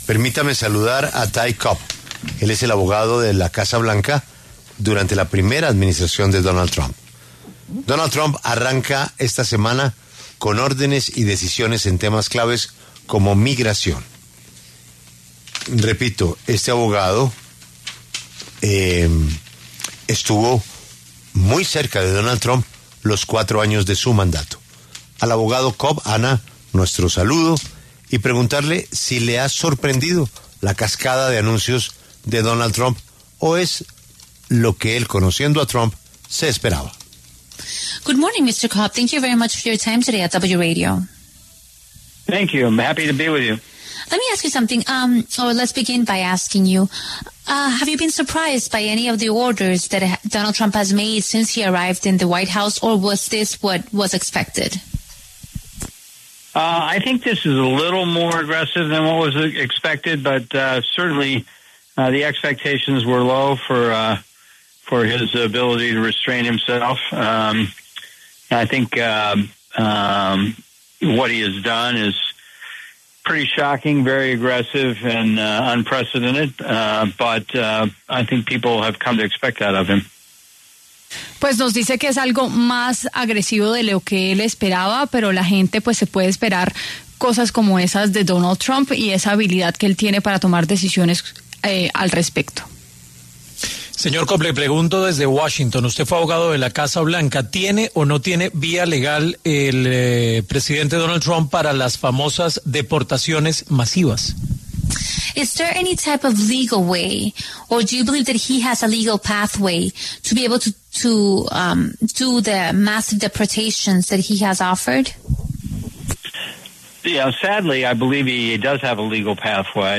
Ty Cobb, abogado en la Casa Blanca durante primera administración Trump, habló en La W, con Julio Sánchez Cristo, ante la ola de medidas que tomó el presidente republicano en el primer día de su segundo periodo como mandatario de Estados Unidos.